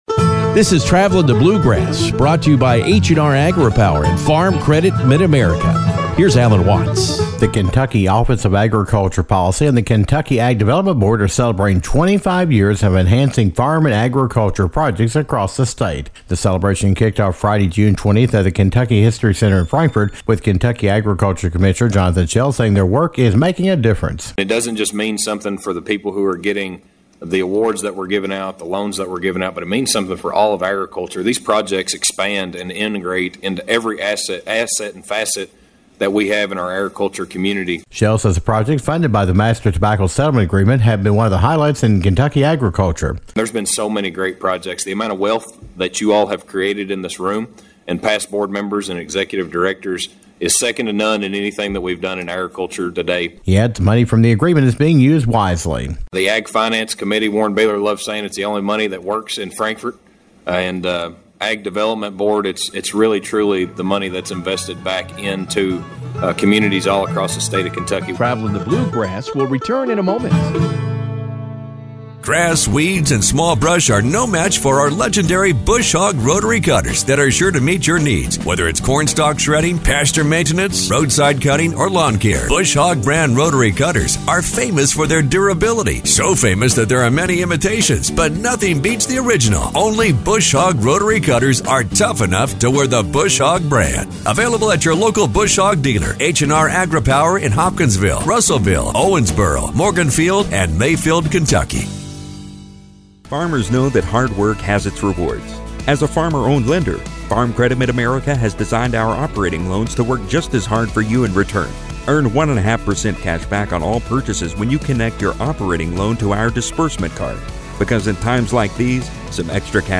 The Kentucky Agriculture Development Board and Kentucky Office of Agriculture Policy is celebrating twenty-five years of supporting agriculture. Kentucky Agriculture Commissioner Jonathon Shell spoke about the history during the June meeting of the Kentucky Ag Development Board at the Kentucky History Center in Frankfort.